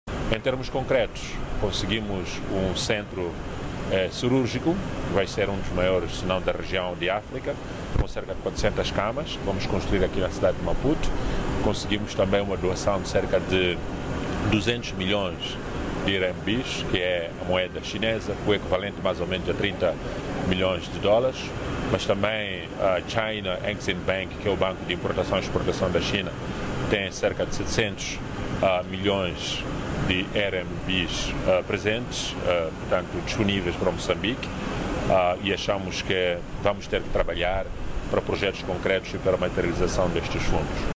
Declarações do Presidente moçambicano, ontem, no Aeroporto Internacional de Maputo, à chegada da China, onde efetuou uma visita de Estado de 7 dias.